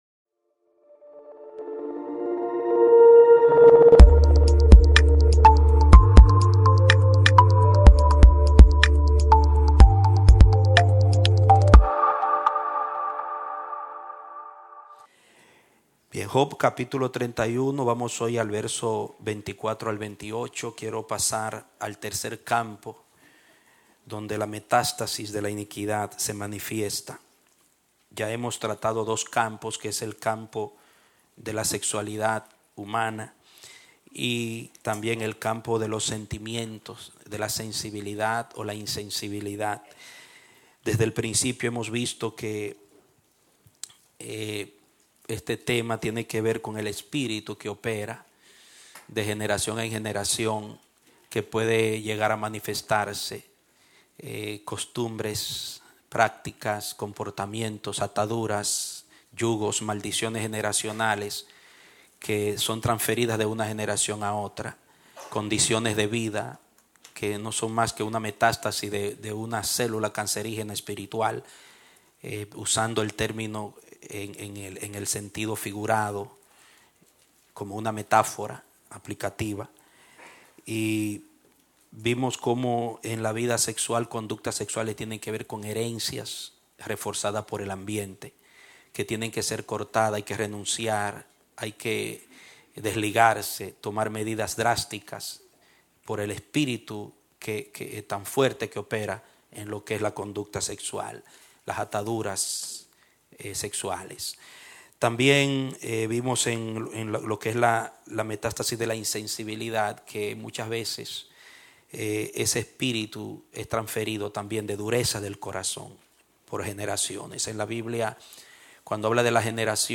Prédicas – El Amanecer de la Esperanza Ministry